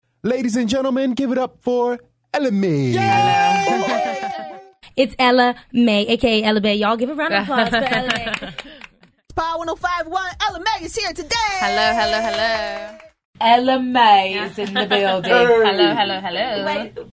エラ・メイ
ラジオ出演時の発音 （※Ella Mai a.k.a. Ella Bae が聴き取れます）